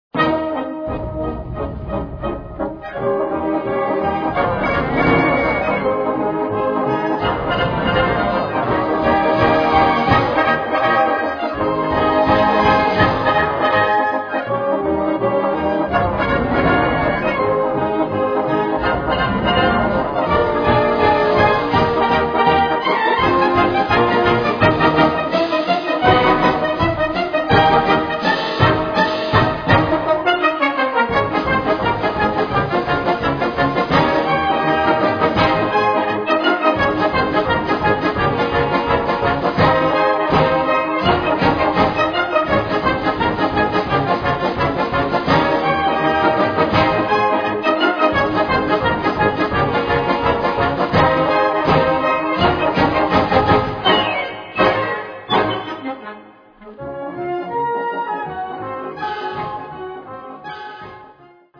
Gattung: Schnellpolka
Besetzung: Blasorchester